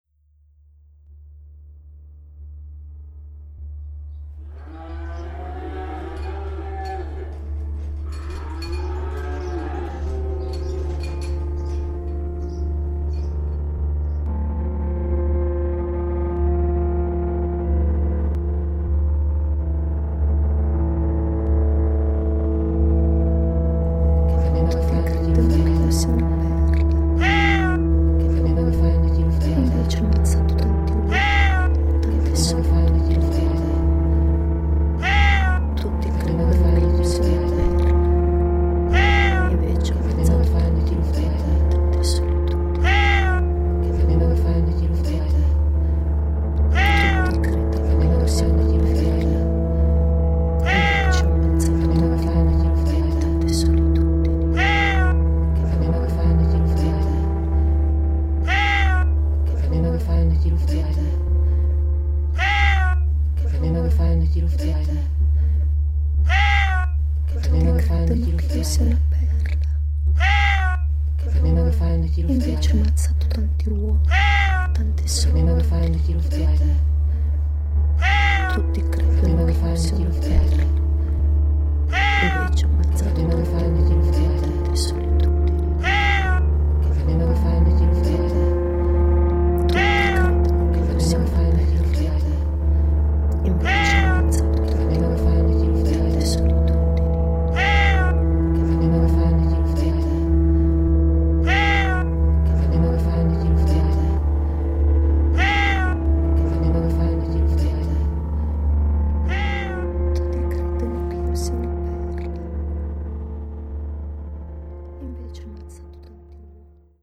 Ὀρφεύς Orfeo ein der italienischen Dichterin Alda Merini gewidmetes Konzert
März 2012, um 20 Uhr Roten Salon der Volksbühne am Rosa-Luxemburg Platz
Orfeo als Konzert mit drei vokalen Performerinnen und zwei Sound-Designern, die in einem Zustand gegenseitigen Hörens arbeiten, um Leben in ein Wort zu geben, das nicht von Ton begleitet wird, und nicht von einem Ton, der als musikalischer Untergrund gilt.